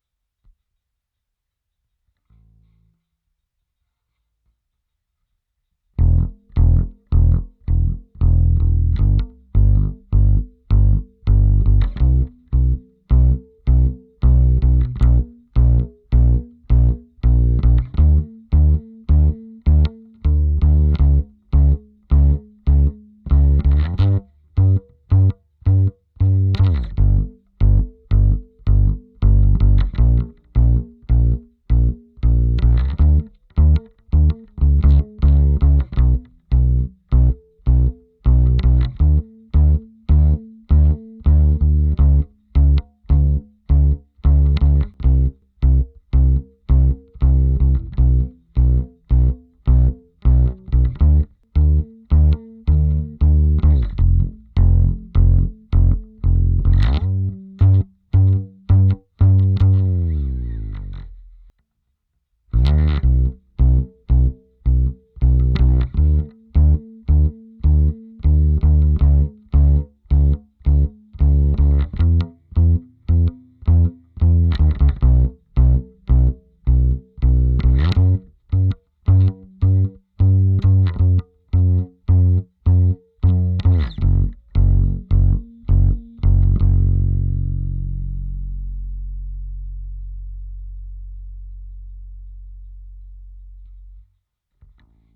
Upright Clean.01.Comp_01.wav